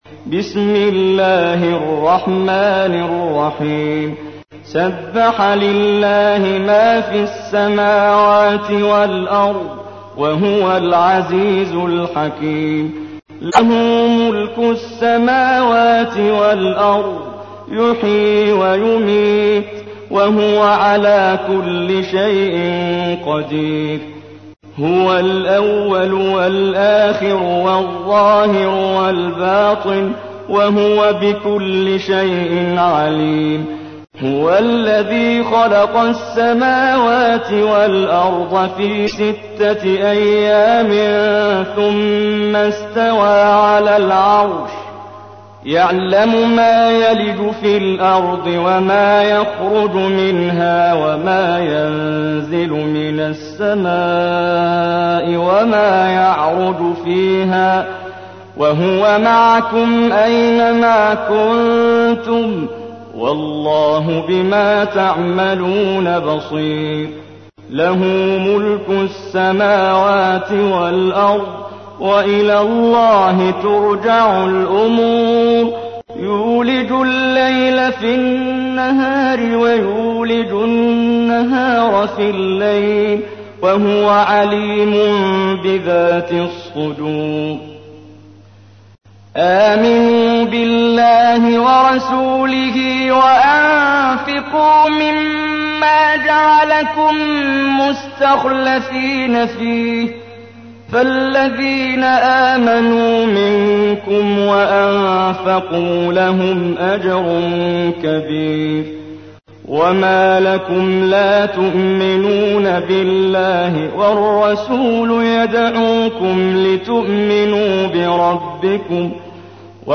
تحميل : 57. سورة الحديد / القارئ محمد جبريل / القرآن الكريم / موقع يا حسين